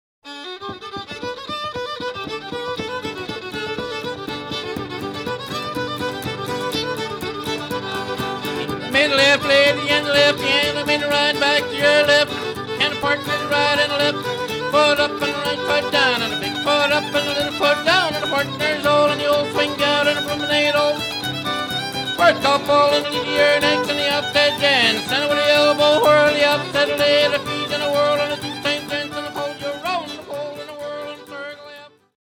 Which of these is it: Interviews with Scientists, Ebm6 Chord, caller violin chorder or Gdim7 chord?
caller violin chorder